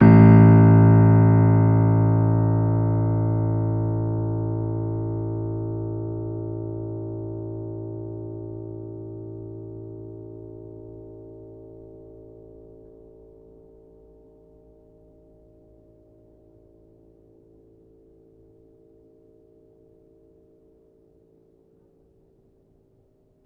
healing-soundscapes/Sound Banks/HSS_OP_Pack/Upright Piano/Player_dyn3_rr1_006.wav at main